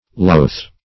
Loath \Loath\ (l[=o]th), a. [OE. looth, loth, AS. l[=a][eth]